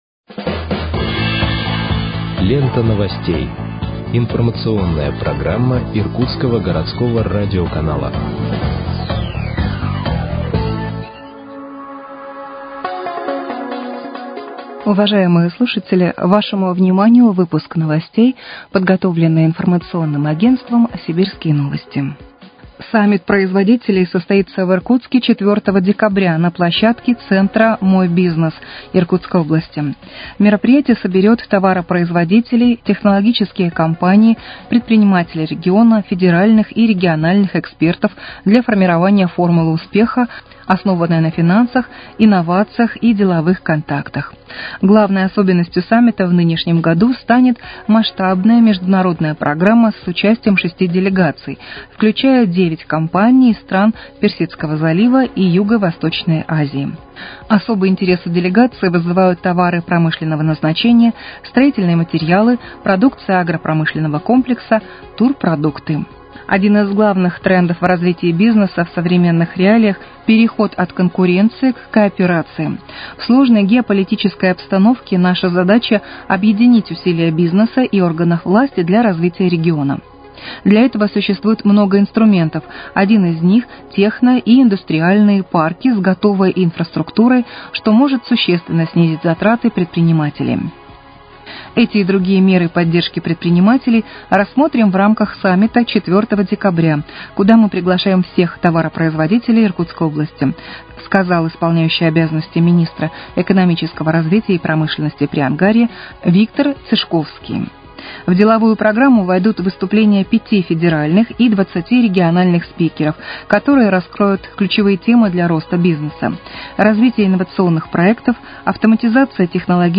Выпуск новостей в подкастах газеты «Иркутск» от 21.11.2025 № 2